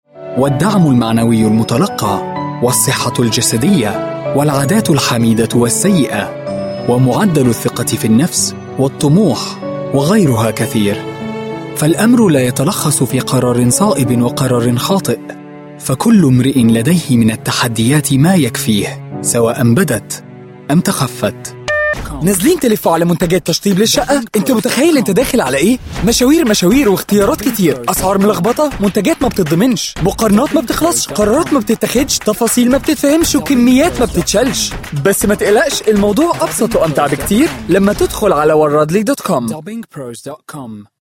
Voice Bank
Professional Arabic Voice Over and Arabic Dubbing Service